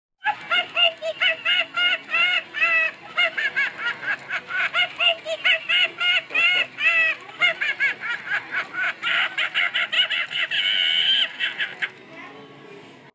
Laughing Sock Monkey – Audio Donut
laughing-sock-monkey.m4a